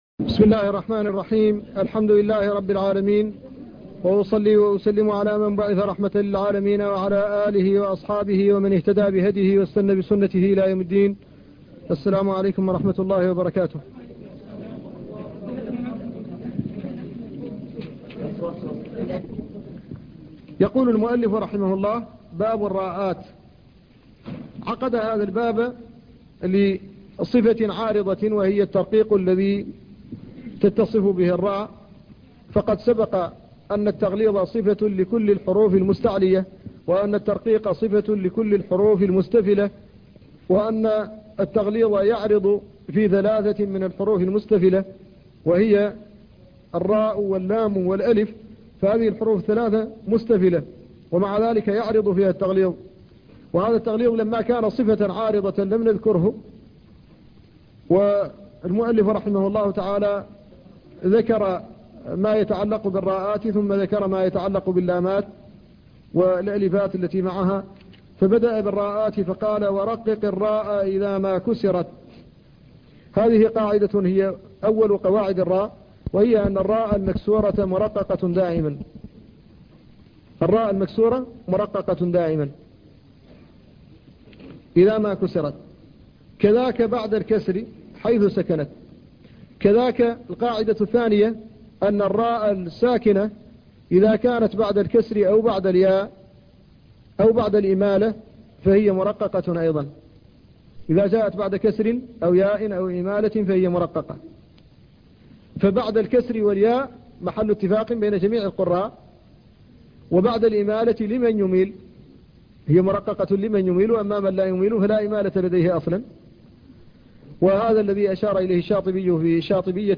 الدرس السابع - شرح متن الجزرية في التجويد